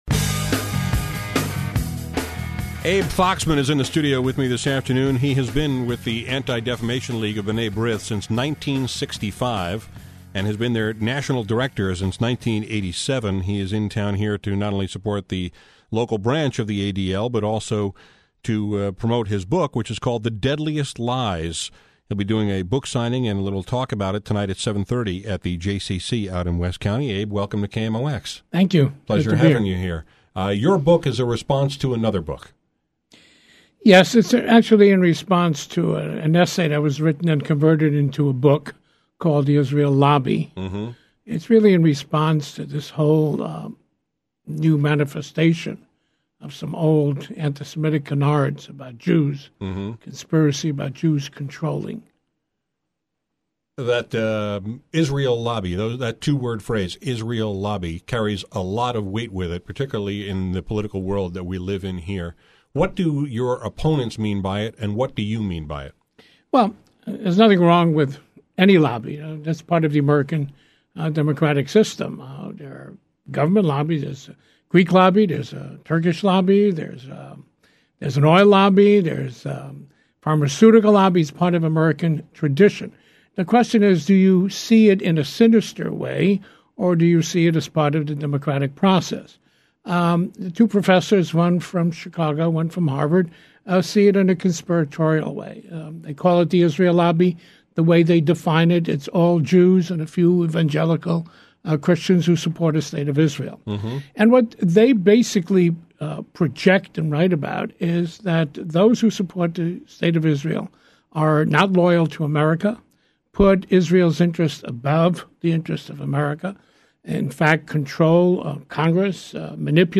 Today I talked with Abe Foxman, who has been national director of the Anti-Defamation League for the last 20 years. We discussed his book, “The Deadliest Lies,” which is a response to the writing of two professors who blame “the Israel lobby” for many things, including our involvement in Iraq. Foxman addressed that, and the embattled relationship between Israel and Iran, and I asked him why he didn’t support the recent congressional resolution regarding the genocide committed by the Ottoman Empire against Armenians in Turkey.